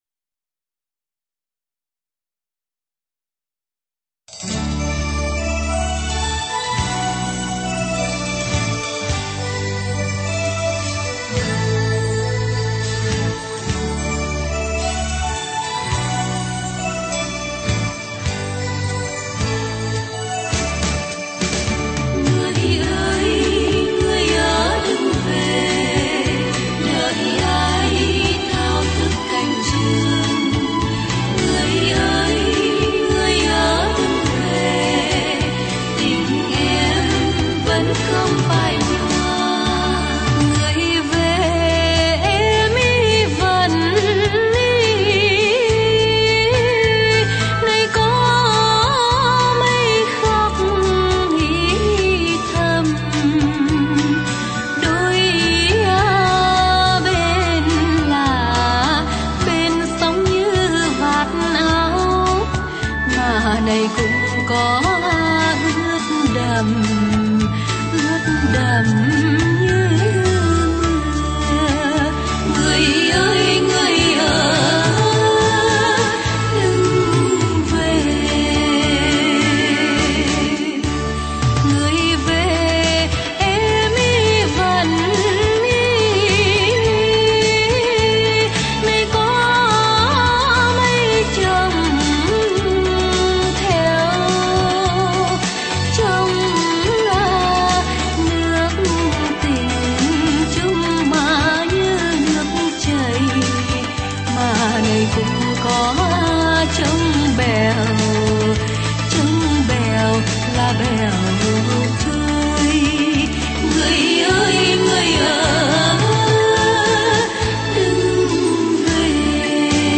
Đại Nhạc Hội Mừng Web 9/4 tròn hai tuổi
Hãy cùng chúng tôi hướng mắt lên sân khấu, nơi ban nhạc Ba Con Gà Rù đang đệm những nốt nhạc luyến láy, du dương những cung trầm bổng réo rắt góp phần quan trọng cho kết quả của đêm Đại Nhạc Hội qui mô tầm cở thế giới này ...